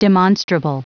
Prononciation du mot demonstrable en anglais (fichier audio)
Prononciation du mot : demonstrable